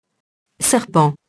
serpent [sɛrpɑ̃]